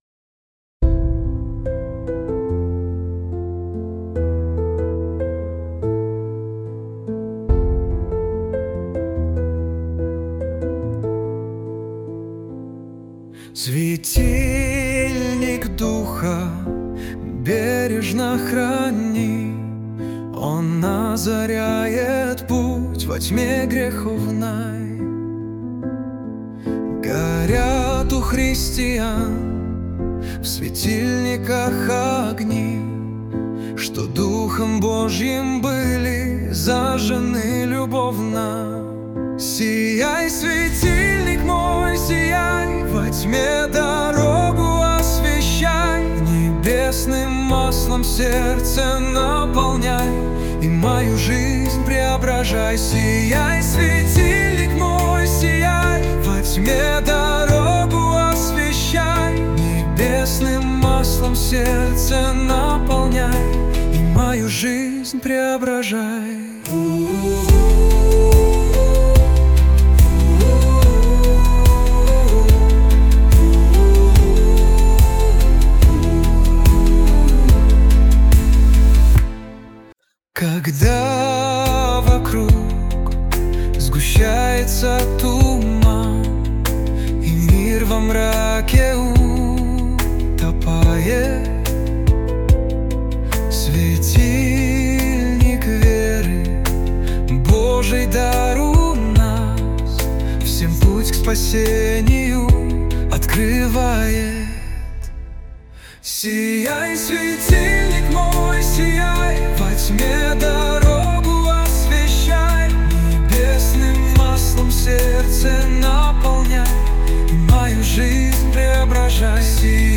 590 просмотров 1862 прослушивания 136 скачиваний BPM: 72